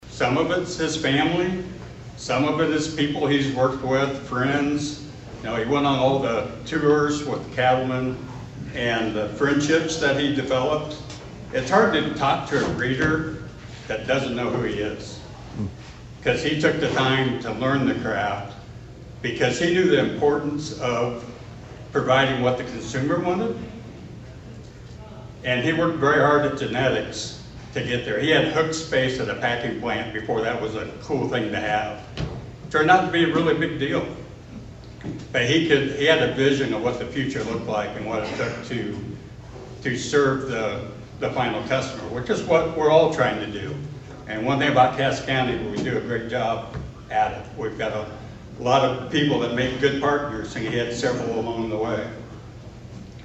(Atlantic) Five area producers were recognized for contributing to the community and cattle industry at the 2024 Cass County Cattleman’s Award Banquet on Saturday night at the Cass County Community Center in Atlantic.